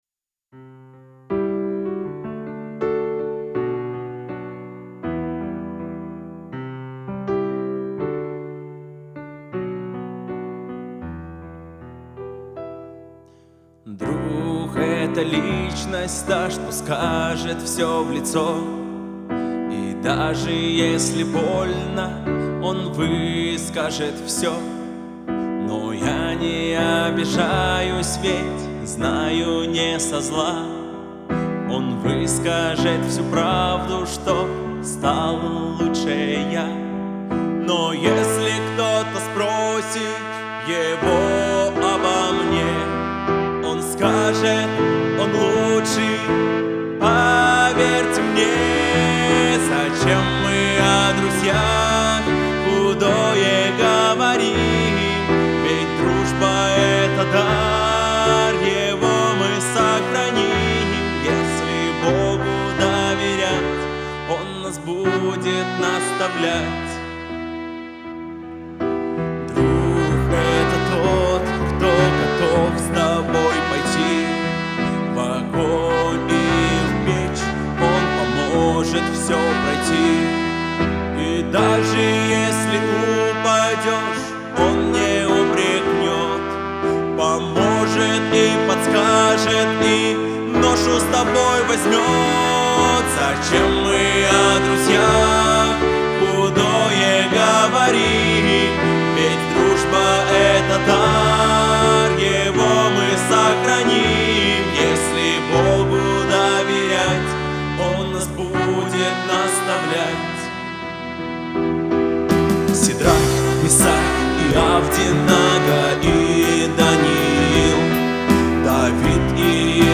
381 просмотр 156 прослушиваний 17 скачиваний BPM: 80